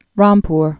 (rämpr)